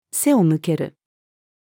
to-pretend-not-to-see-female.mp3